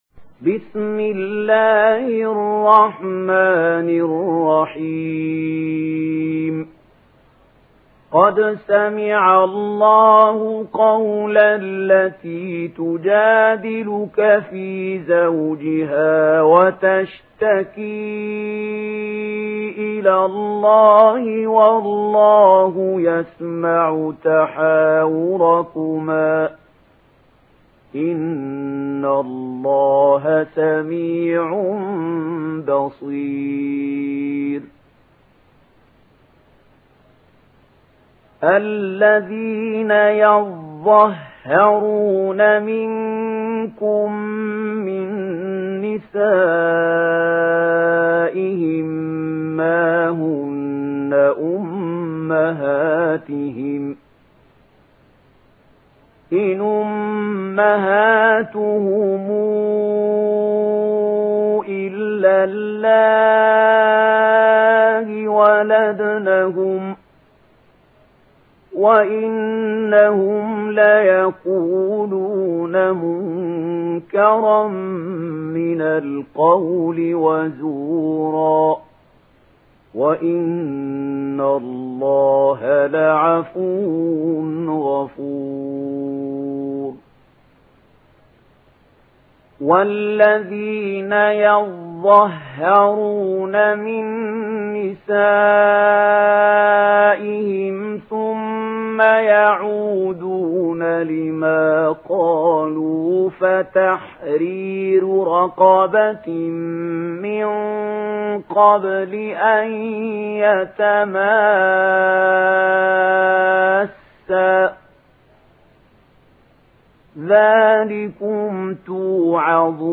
دانلود سوره المجادله mp3 محمود خليل الحصري روایت ورش از نافع, قرآن را دانلود کنید و گوش کن mp3 ، لینک مستقیم کامل